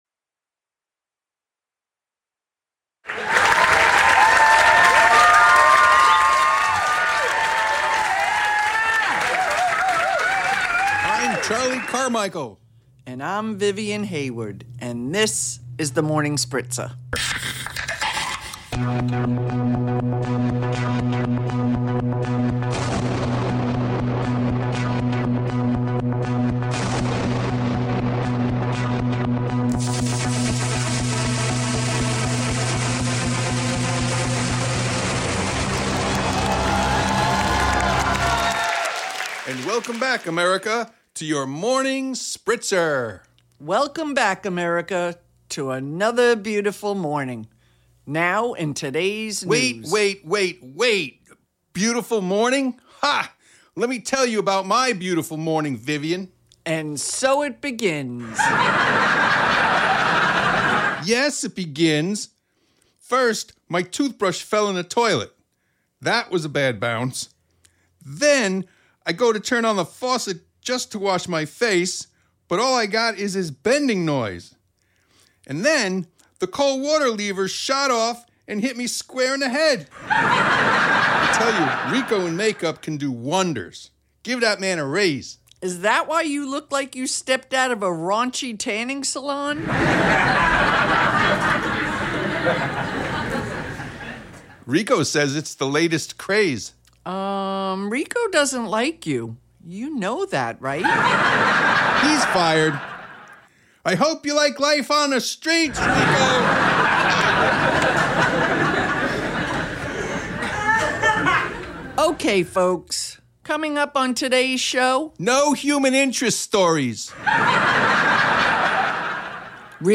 Syndicate Of The Mind is a drama group consisting of the Daytona Beach Postal employees and members of the community.
Also visit our YouTube Channel for 5.1 surround Just click the website link below … continue reading 44 episodes # Audio Drama # Adventures In George School